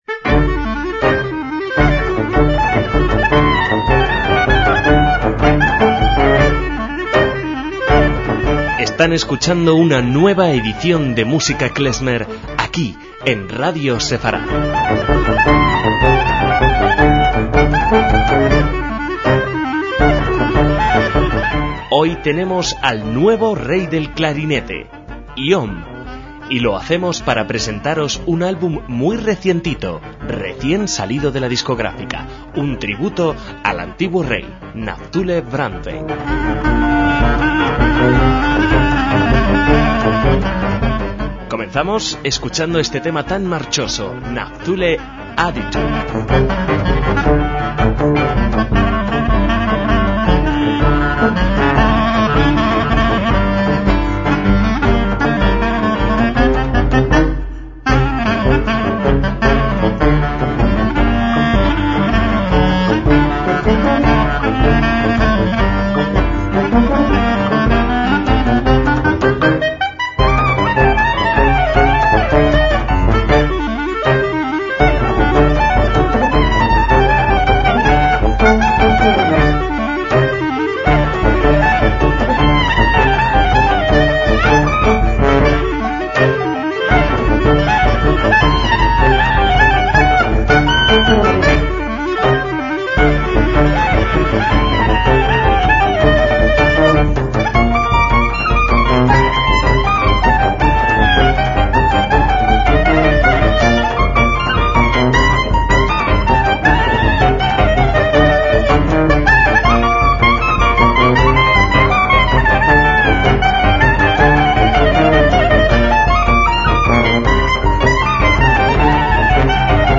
MÚSICA KLEZMER
clarinete